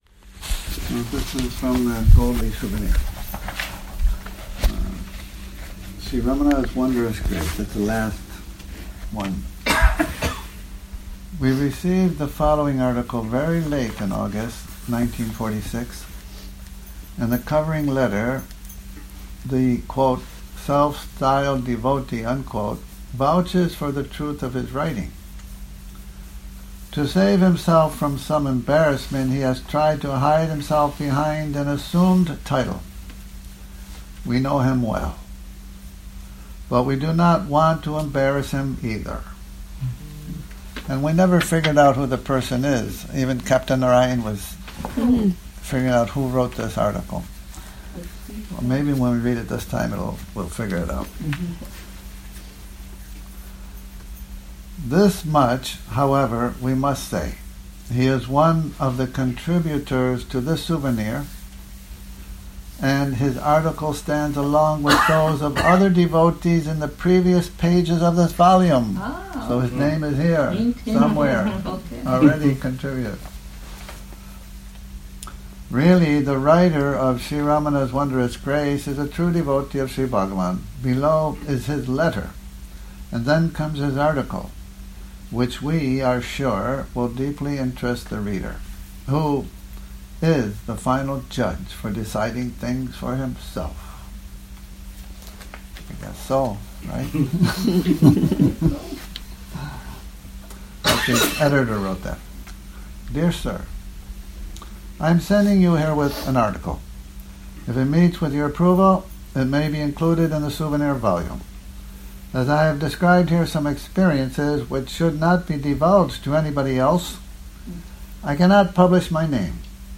Morning Reading, 28 Sep 2019